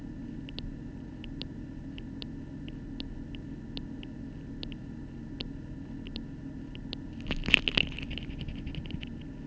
The present paper analyzes the sounds emitted by pre-hatching chicks, focusing on those named as “clicks,” which are thought to mediate pre-hatching social interactions and hatching synchronization.
As hatching approaches, clicks evolve from isolated events to highly organized hierarchical clusters.